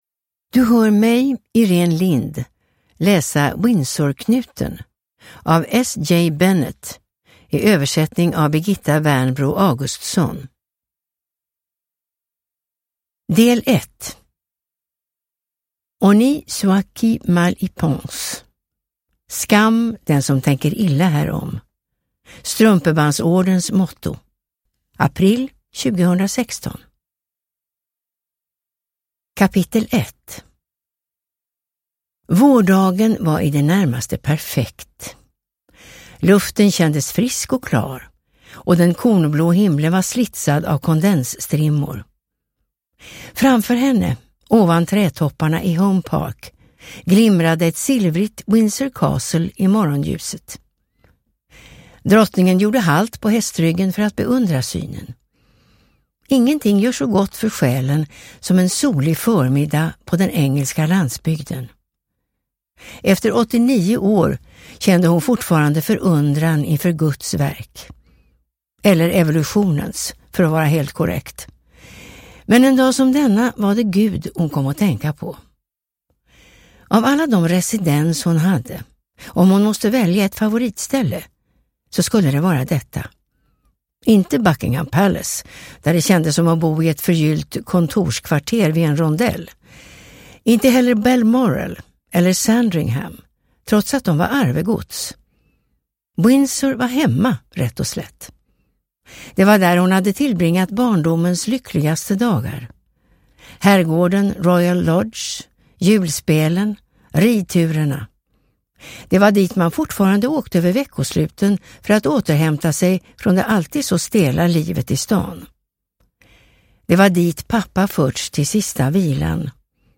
Windsorknuten – Ljudbok